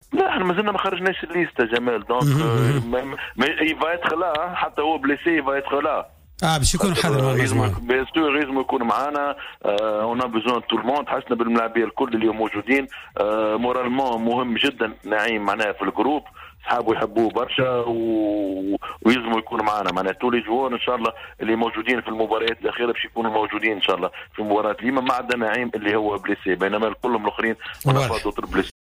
أكد مدرب المنتخب الوطني لكرة القدم نبيل معلول في تصريح خاص براديو جوهرة أف أم أنه سيوجه الدعوة للاعب نعيم السليتي للمشاركة في التربص الأخير للمنتخب بالرغم من ان الإصابة التي تعرض لها مع فريقه ديجون الفرنسي ستمنعه من المشاركة في مقابلة المنتخب التونسي أمام نظيره الليبي يوم 11 نوفمبر القادم في إطار الجولة الختامية من تصفيات مونديال روسيا 2018.